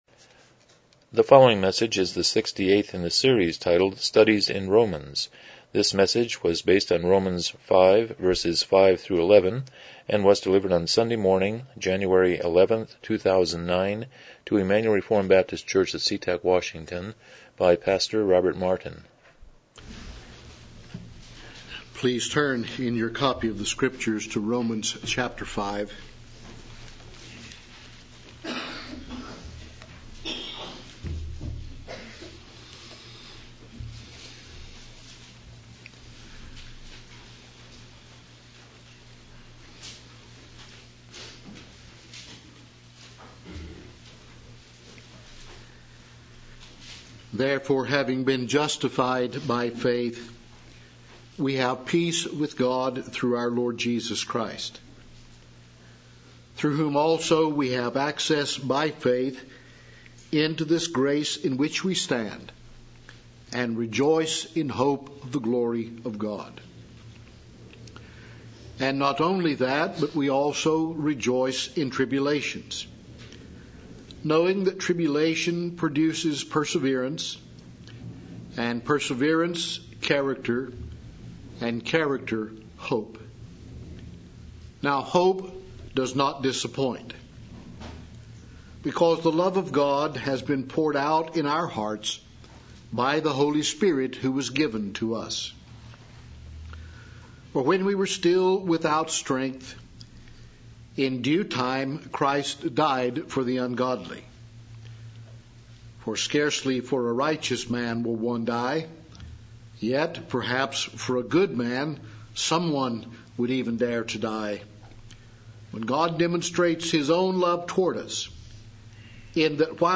Passage: Romans 5:5-11 Service Type: Morning Worship